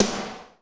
Index of /90_sSampleCDs/Northstar - Drumscapes Roland/SNR_Snares 1/SNR_F_T Snrs x